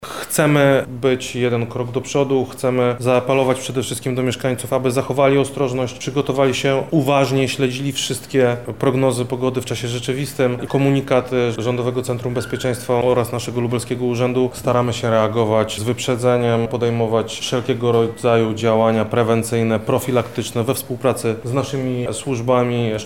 Władze wojewódzkie i służby są postawione w stan gotowości. Więcej o tym mówi Krzysztof Komorski, wojewoda lubelski: